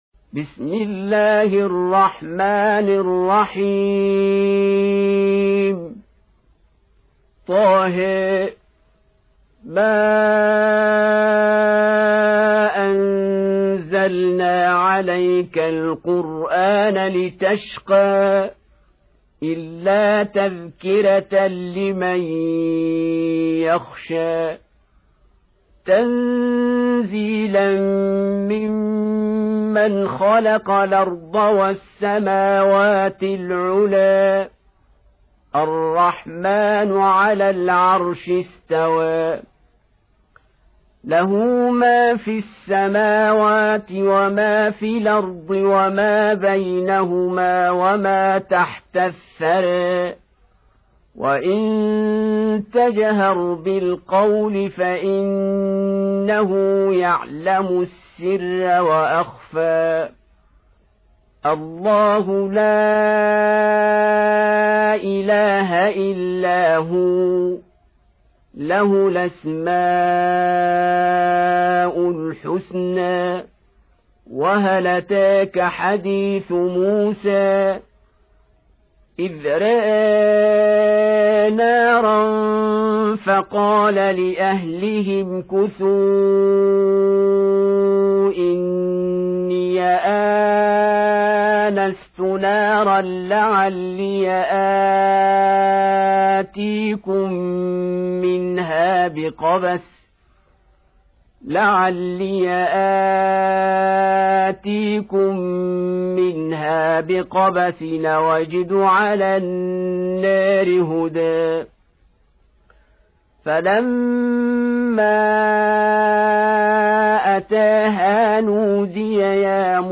20. Surah T�H�. سورة طه Audio Quran Tarteel Recitation
Surah Repeating تكرار السورة Download Surah حمّل السورة Reciting Murattalah Audio for 20.